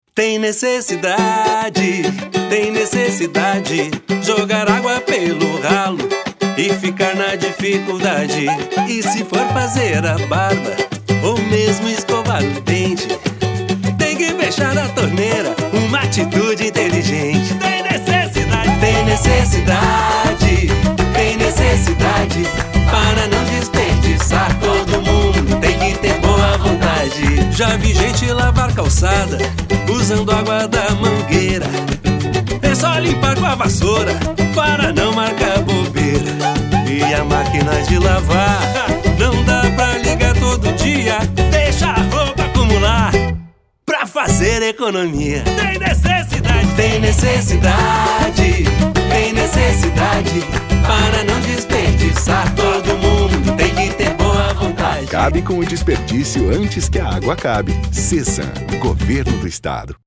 a campanha adota um tom alegre, ao ritmo do samba